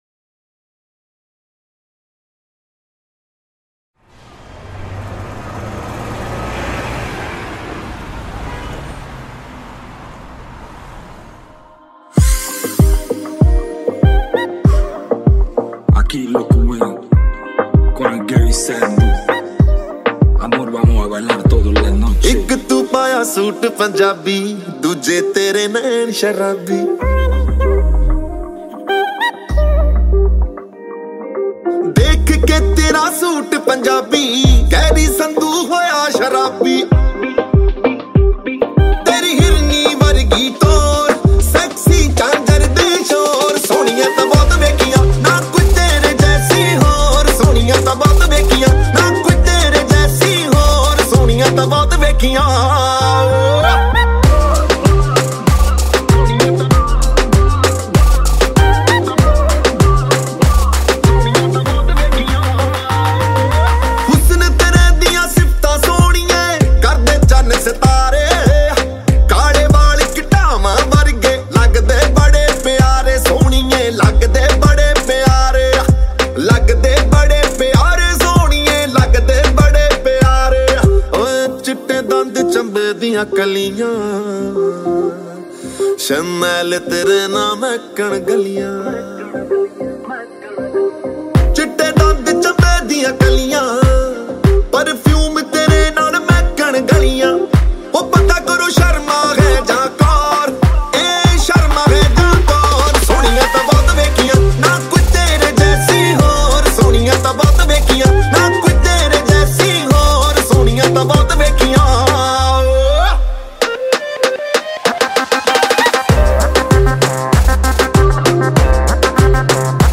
Mp3 Files / Bhangra /